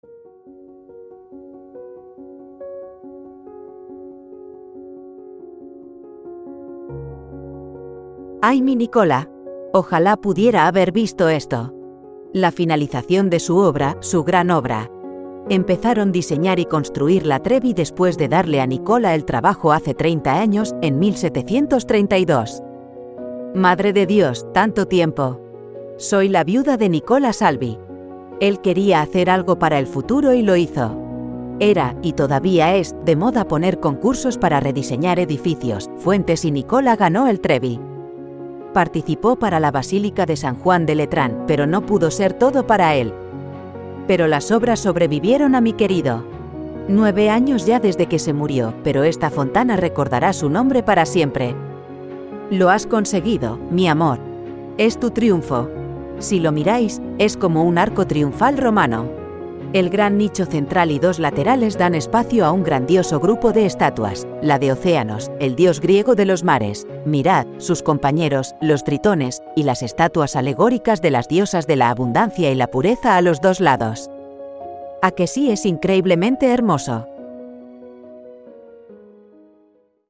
La viuda de Nicola Salvi se recuerda de la Fontana di Trevi, obra de su marido